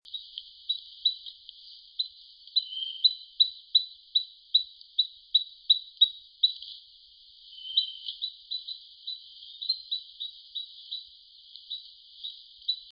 15-5麟趾山口2012march28栗背林鴝c1.mp3
栗背林鴝 Tarsiger johnstoniae
南投縣 信義鄉 玉山麟趾山口
錄音環境 灌木叢
雄鳥
錄音: 廠牌 Denon Portable IC Recorder 型號 DN-F20R 收音: 廠牌 Sennheiser 型號 ME 67